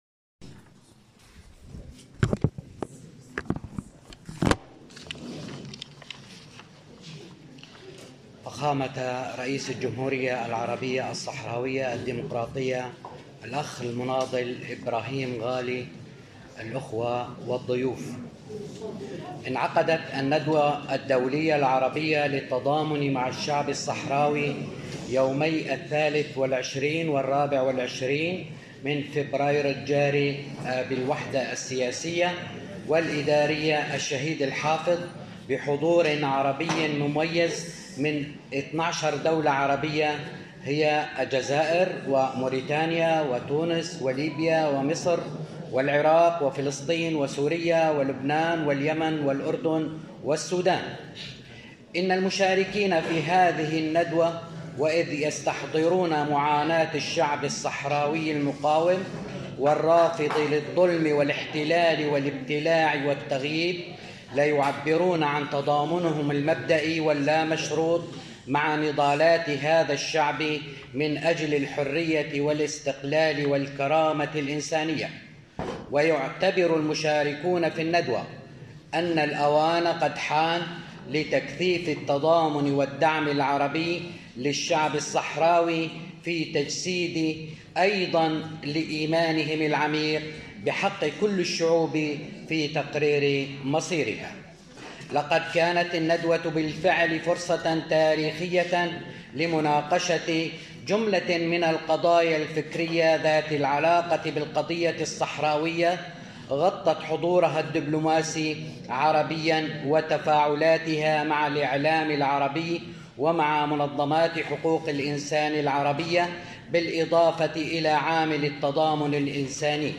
كلمة الرئيس الصحراوي إبراهيم غالي خلال ختام أشغال الندوة العربية للتضامن مع الشعب